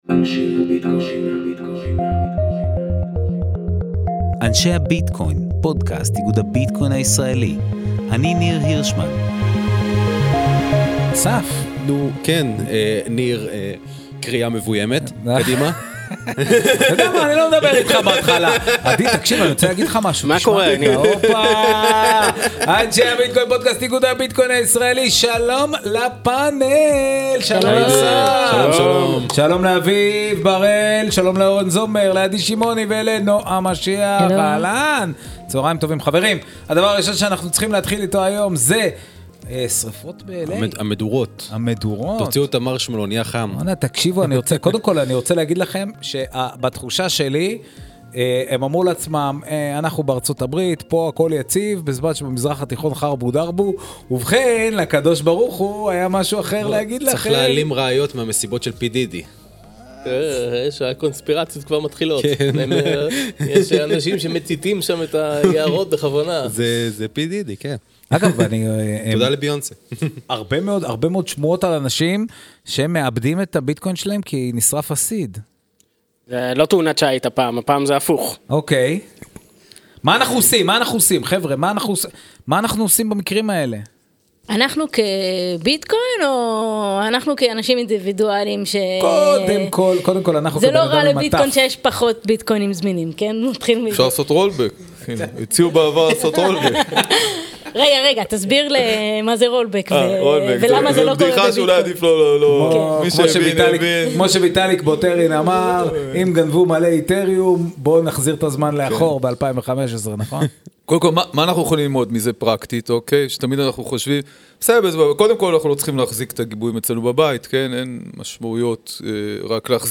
פאנל המומחים – על שריפות, רזרבה וצ'יינקוד